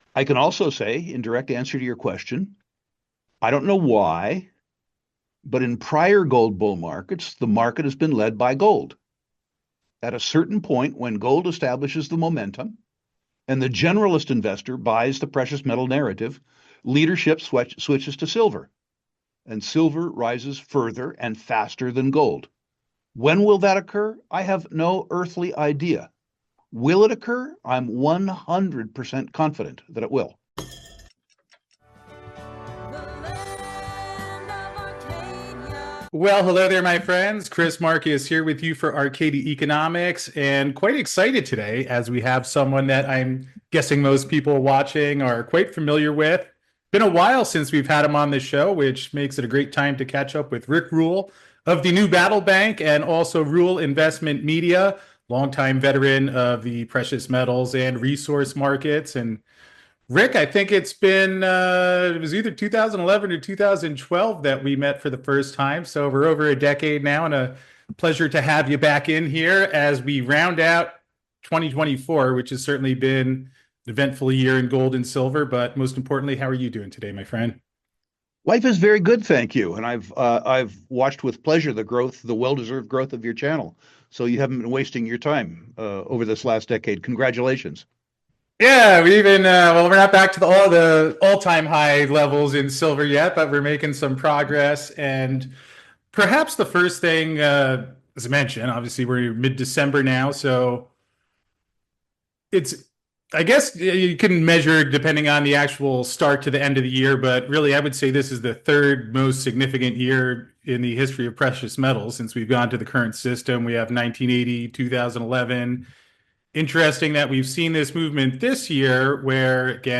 So to hear from someone who was involved with one of the largest silver trusts when all of this happened, click to listen to this important precious metals interview now!